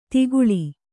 ♪ tiguḷi